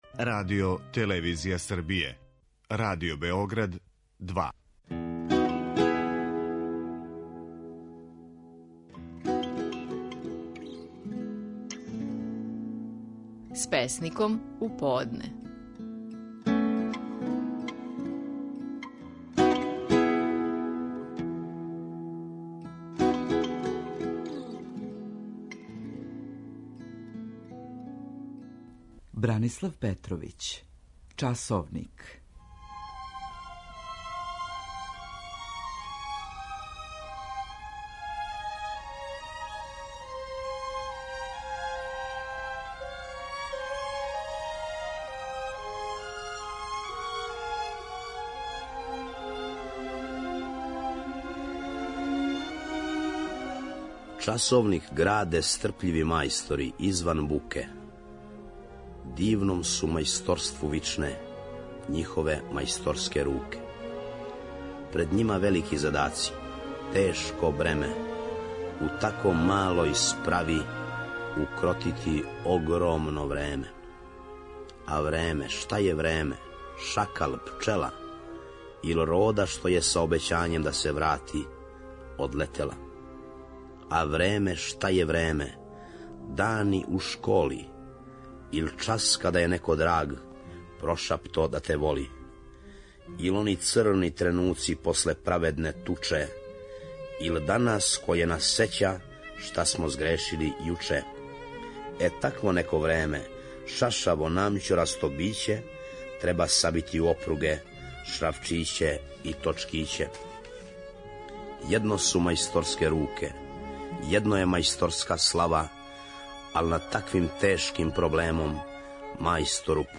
Стихови наших најпознатијих песника, у интерпретацији аутора.
У данашњој емисији, можете чути како је своју песму „Часовник" казивао Бранислав Петровић.